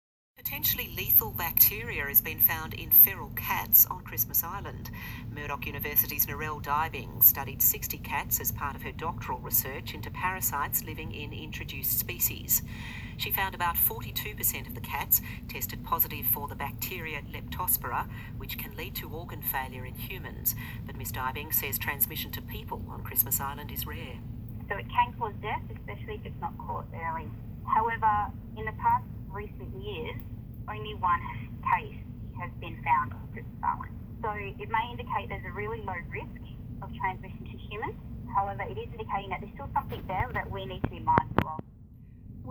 abc-interview-19-1-16.m4a